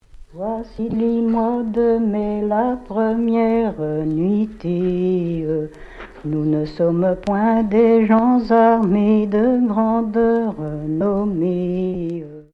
Origine : Mayenne (Haut-Anjou) Année de l'arrangement : 2021